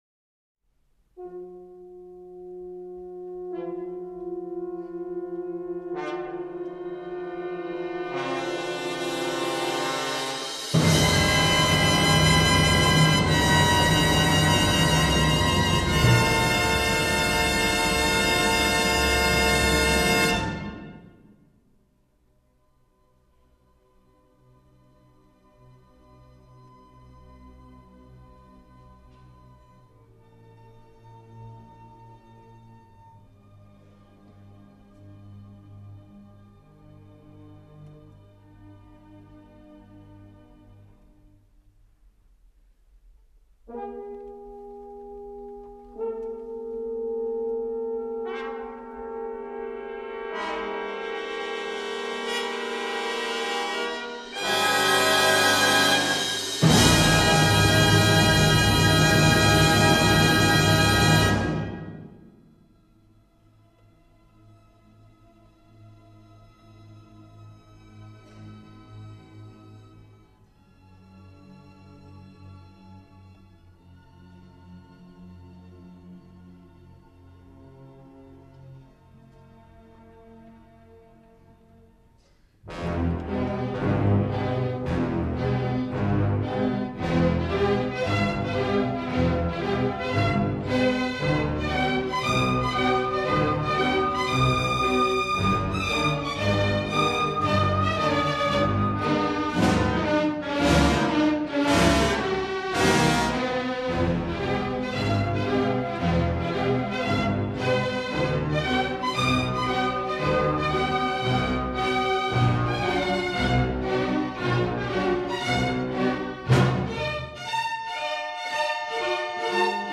Punktyrinis refrenas atspindi neapykantą ir keršto siekį.